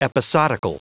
Prononciation du mot episodical en anglais (fichier audio)
Prononciation du mot : episodical